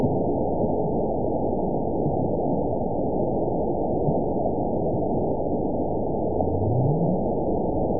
event 920298 date 03/13/24 time 03:13:24 GMT (1 year, 1 month ago) score 9.70 location TSS-AB02 detected by nrw target species NRW annotations +NRW Spectrogram: Frequency (kHz) vs. Time (s) audio not available .wav